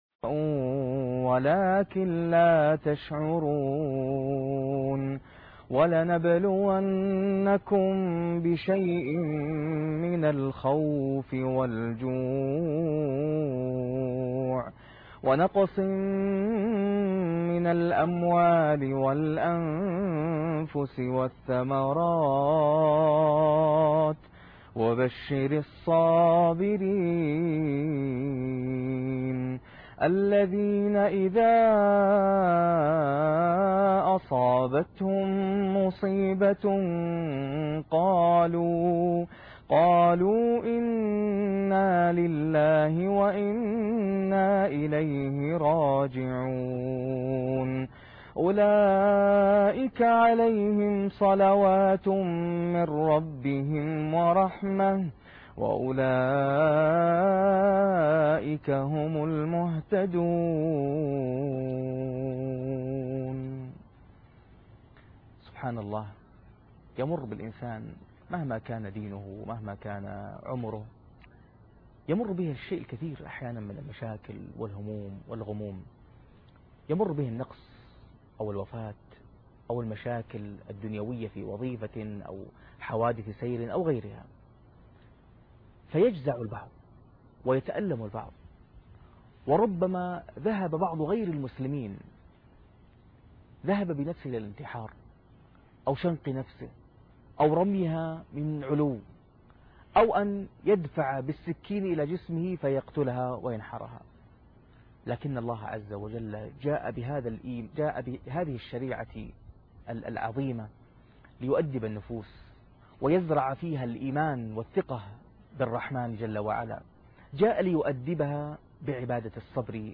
الصبر والصلاة (11/7/2013) ثنائيات قرأنية - القاريء ناصر القطامي